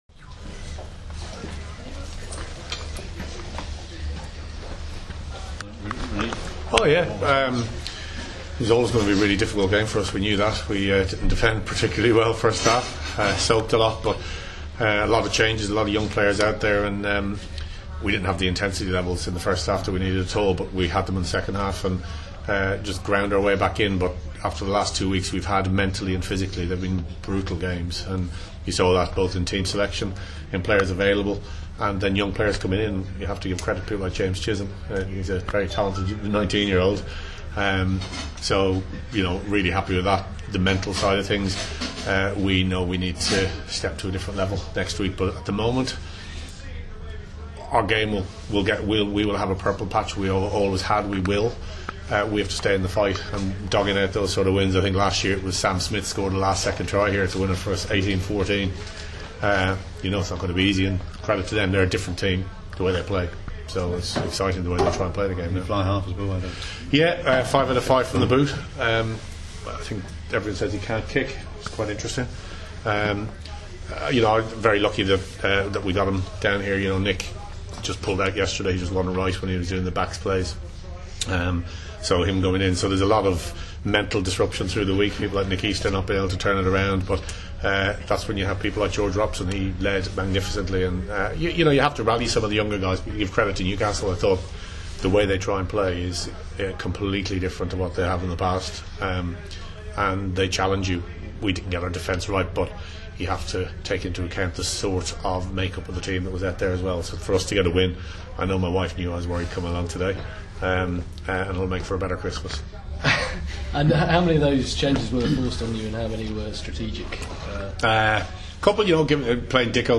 Conor O'Shea, speaking after Harlequins victory against Newcastle at the Stoop on Saturday